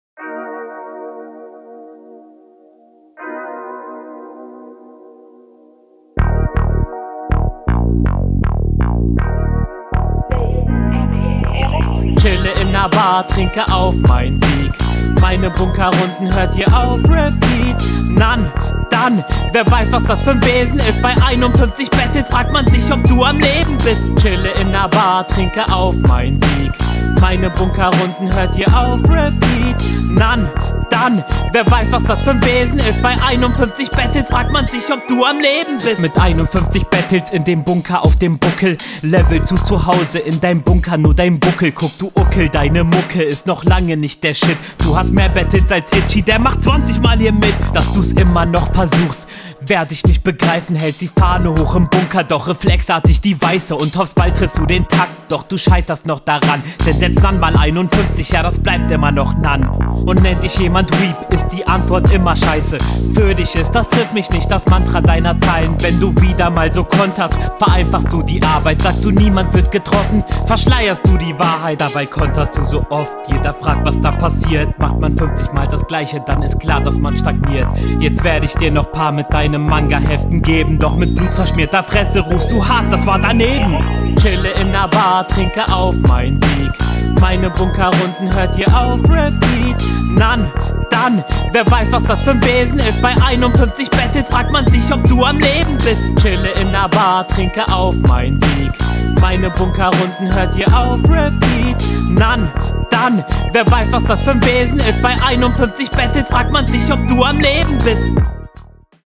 Diese Hookmische...